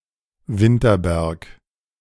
Winterberg (German pronunciation: [ˈvɪntɐˌbɛʁk]
De-Winterberg.ogg.mp3